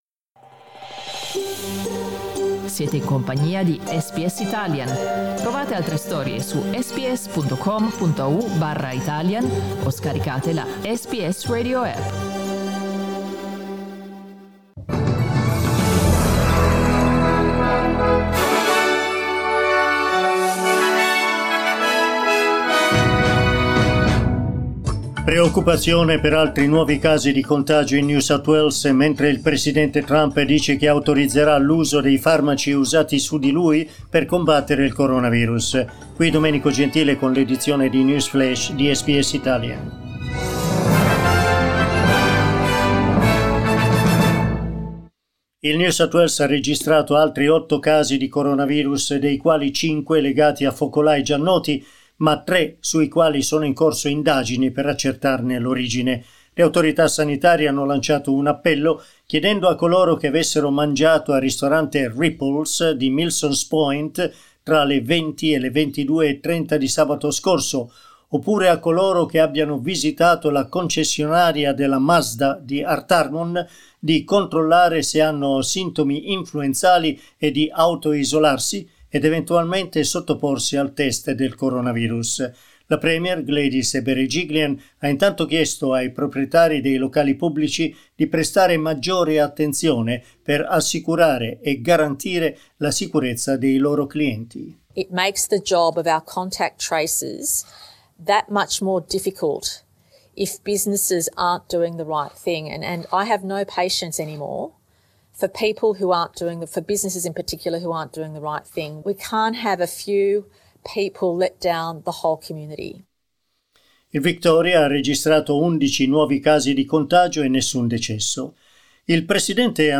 News update of SBS Italian.